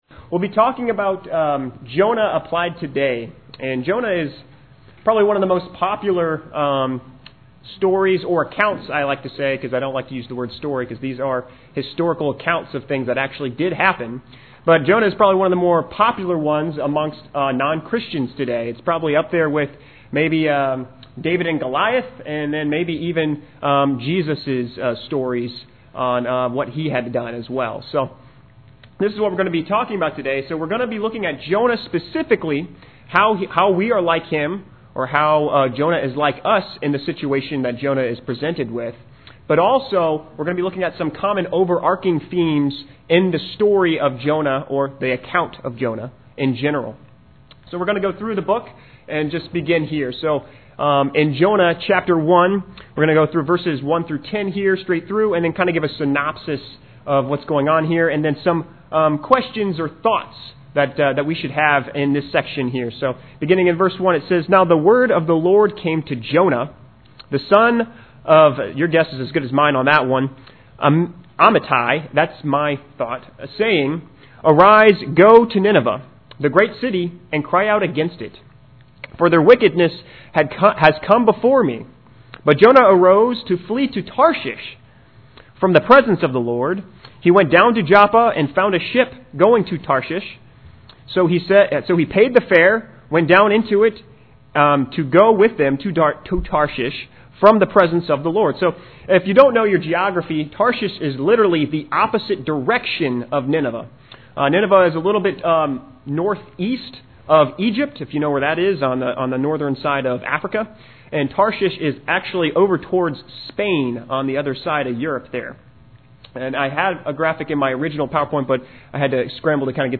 We were happy to have him and enjoyed his lesson on Jonah.